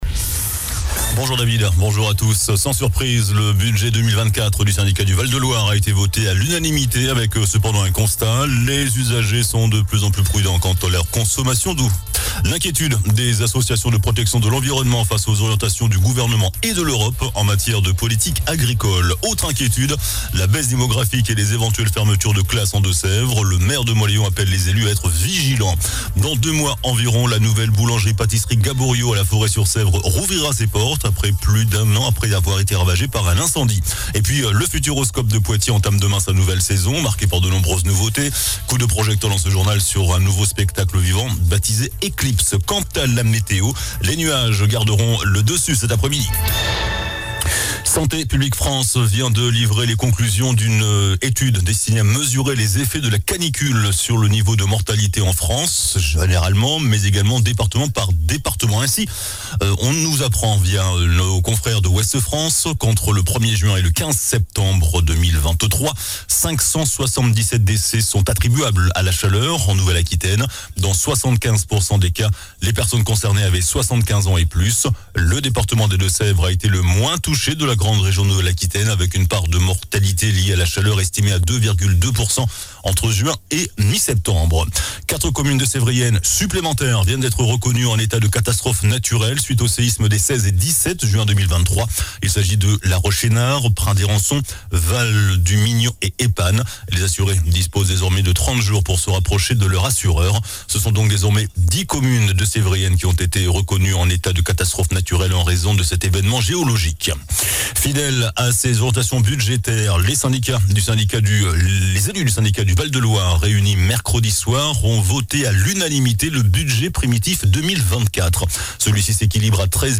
JOURNAL DU VENDREDI 09 FEVRIER ( MIDI )